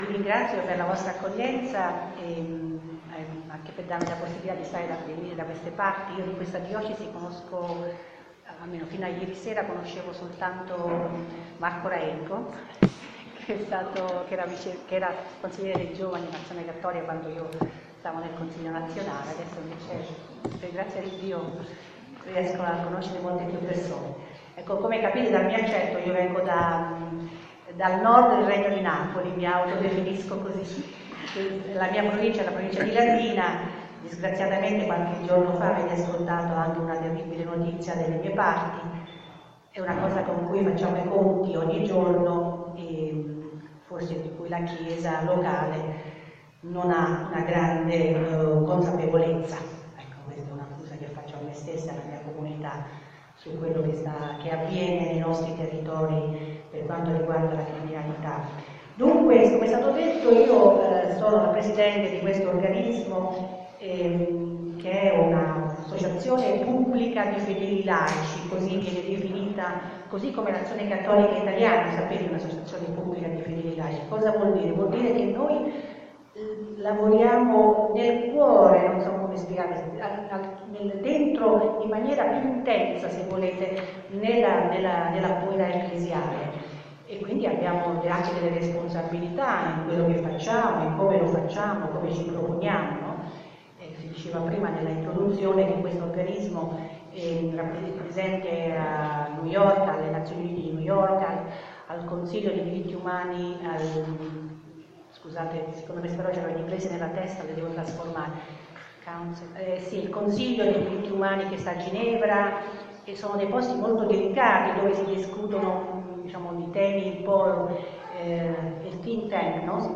Festa Diocesana Adulti 2015
Intervento